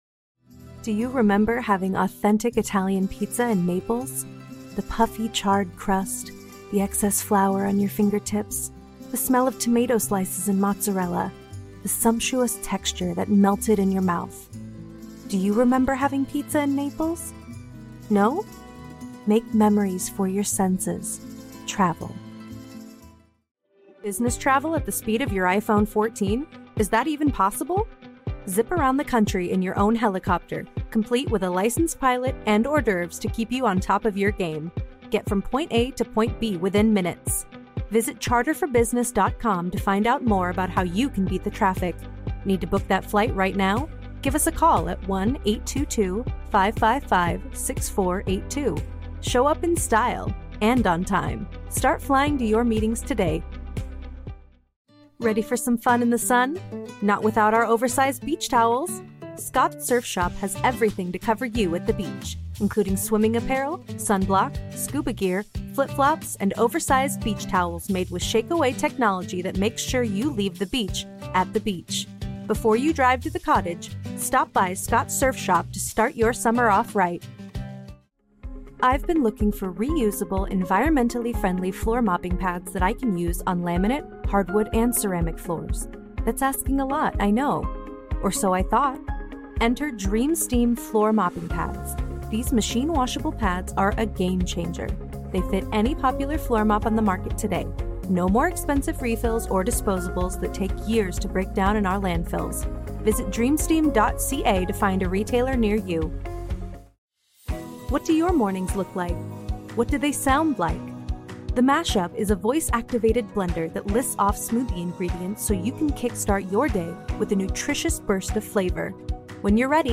Female
Yng Adult (18-29), Adult (30-50)
My voice is clear, articulate, and genuine. My natural speaking voice is medium toned with a neutral American accent.
Character / Cartoon
Character Demo Reel